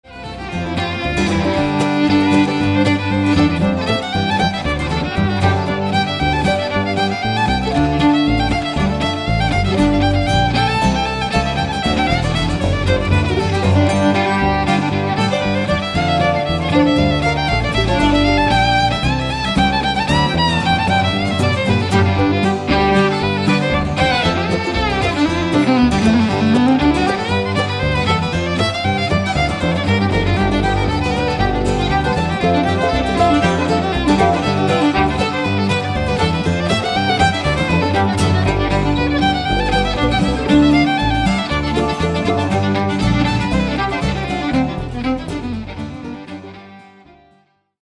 --bluegrass - folk - original music